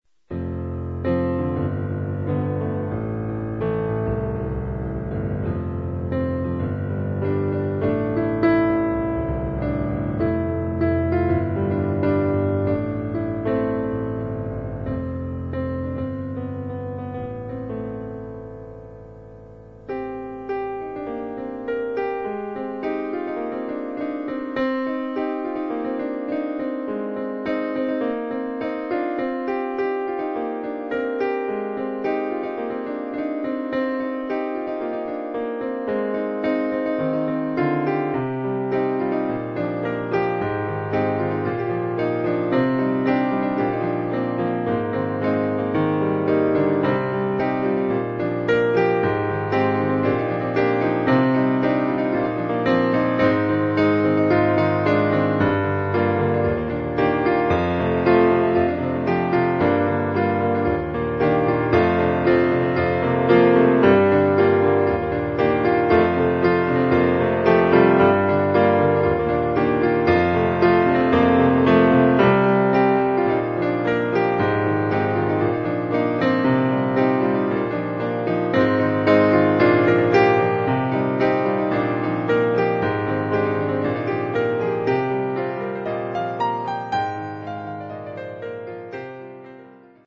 I decided that just because I've lost all interest in writing doesn't mean that I should stop recording those little musical doodles and posting them here.